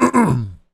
raclement.ogg